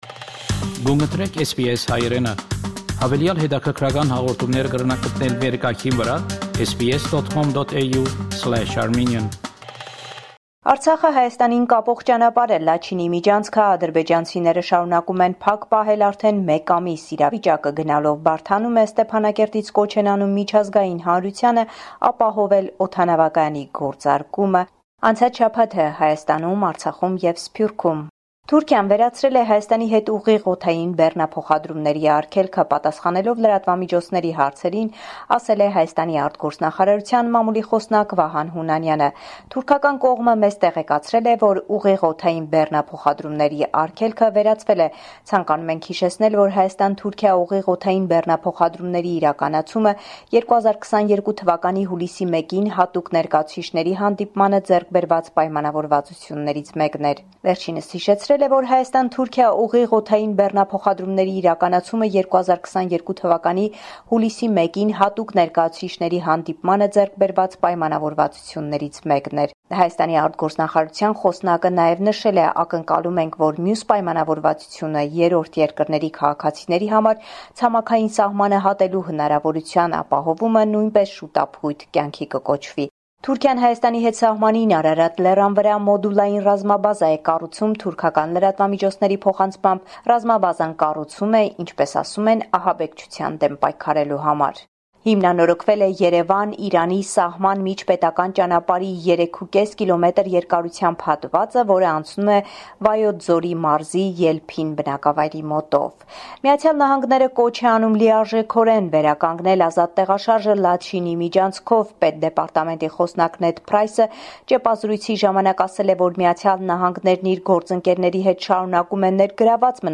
Latest News from Armenia – 10 January 2023